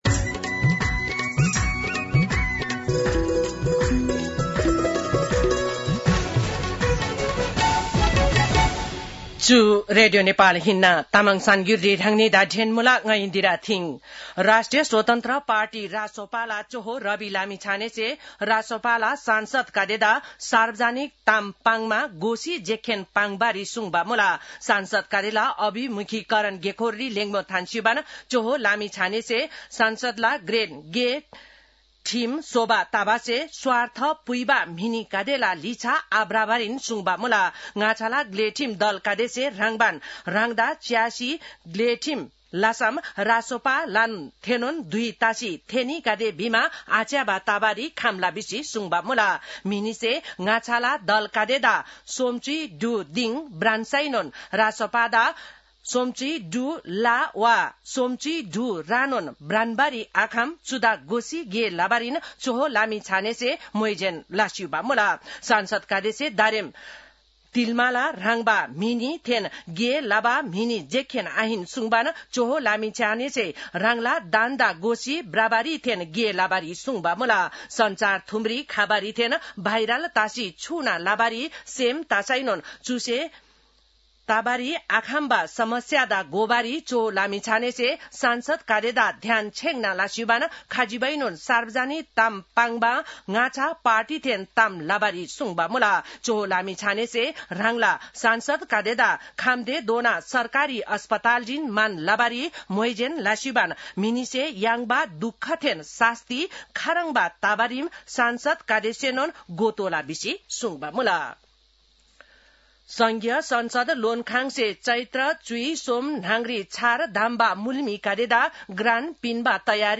तामाङ भाषाको समाचार : ४ चैत , २०८२